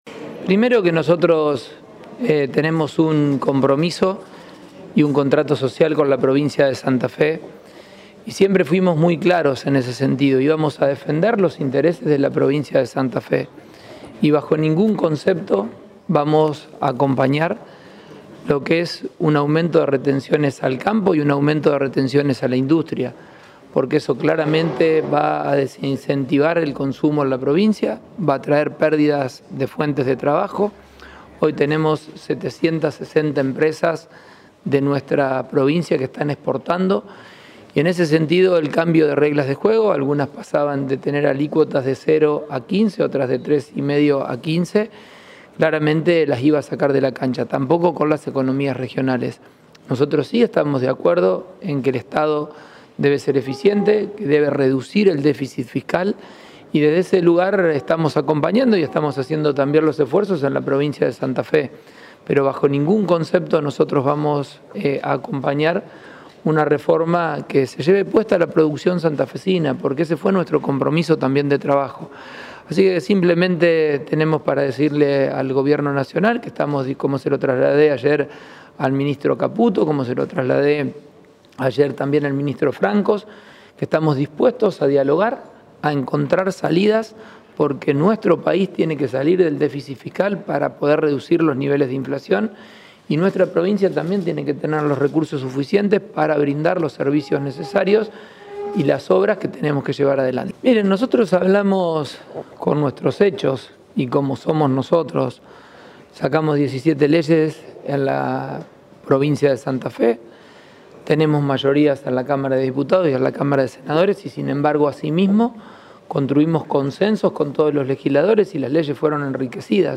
Fue en el hall central del sindicato de Luz y Fuerza, en la ciudad capital, previo a la entrega de escrituras por parte del Ministerio de Igualdad y Desarrollo Social.
Declaraciones Pullaro